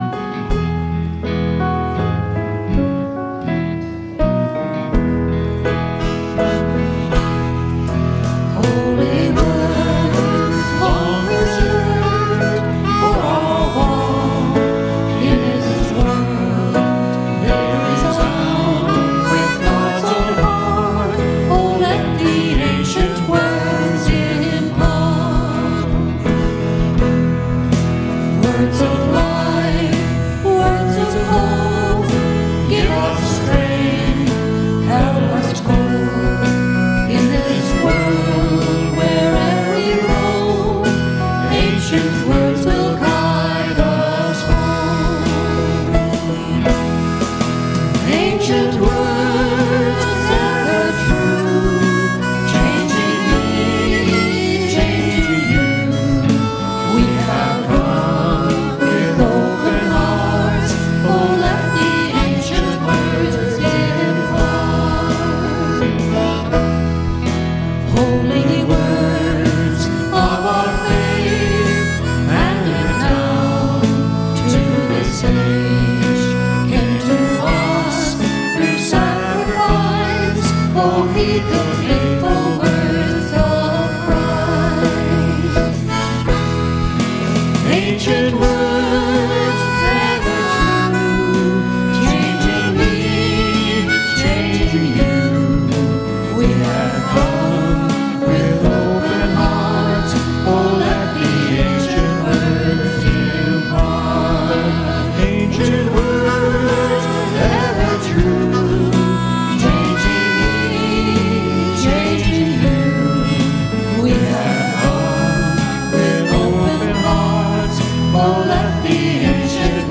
WORSHIP - 10:30 a.m. Fourth Sunday of Advent